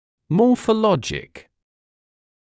[ˌmɔːfə’lɔʤɪk((ə)l)][ˌмоːфэ’лодик((э)l)]морфологический (также morphological)